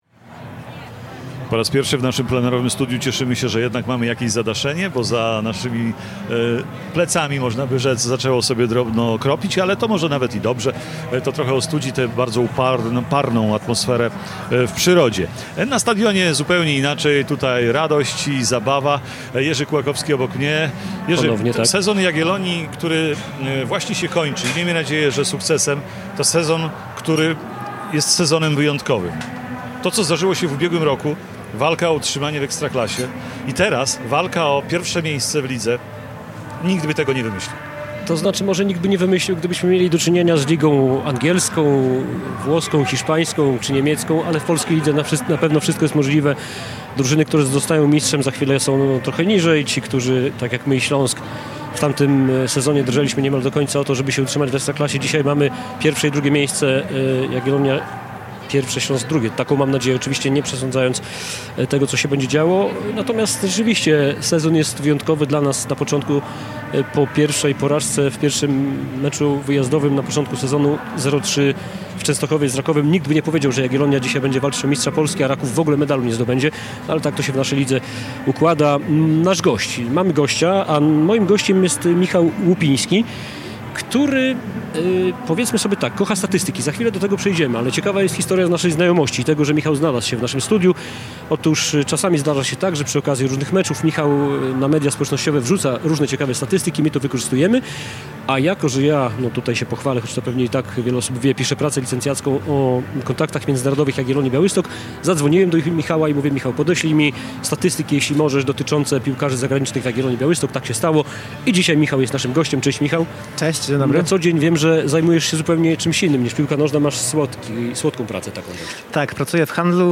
Nasz program rozpoczęliśmy o 14:00 z plenerowego studia na stadionie miejskim w Białymstoku.